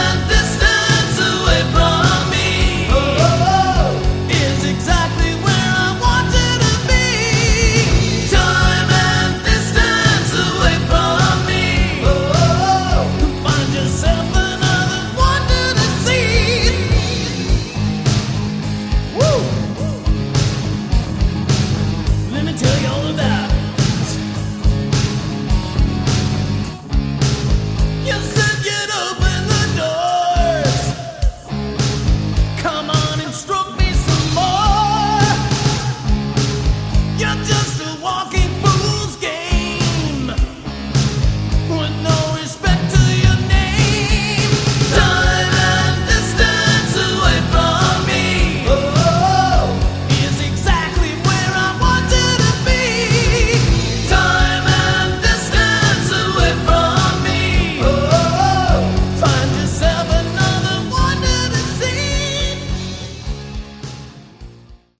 Category: Hard Rock
vocals, acoustic guitar
keyboards, guitar
drums, backing vocals
The rest is demo sound quality.